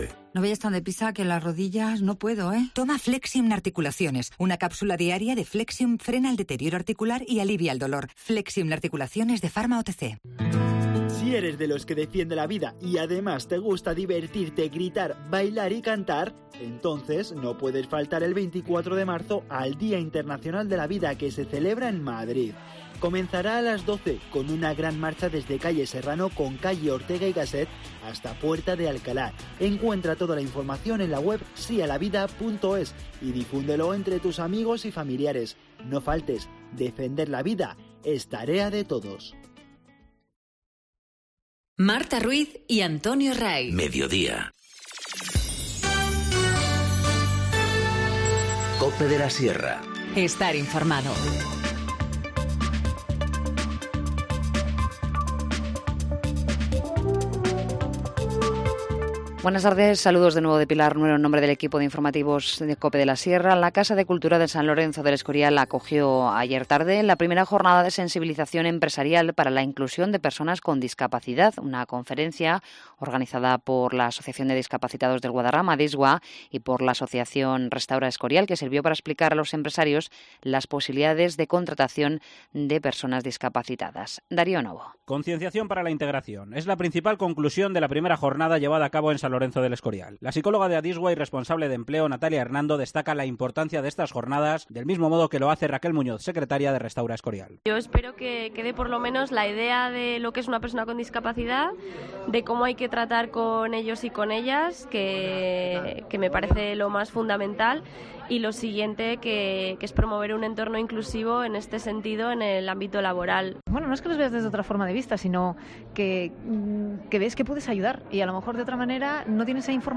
Informativo Mediodía 22 marzo 14:50h